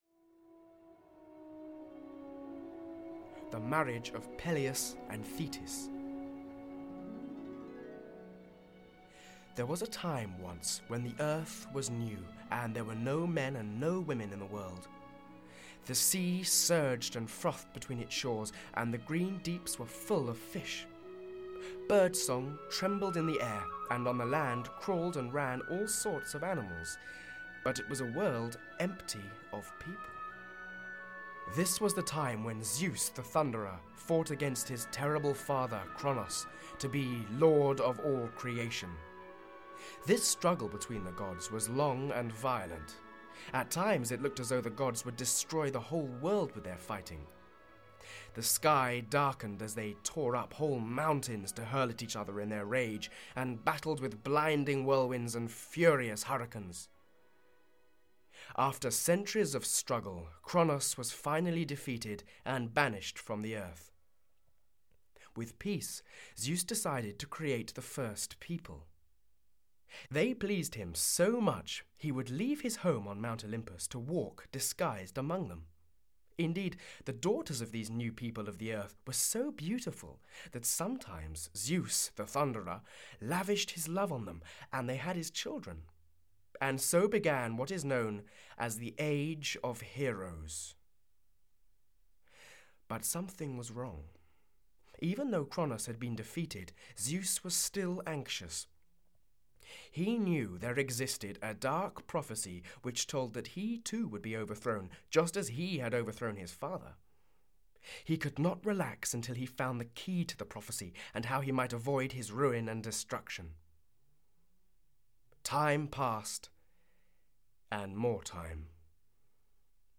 Audio knihaThe Tale of Troy (EN)
Ukázka z knihy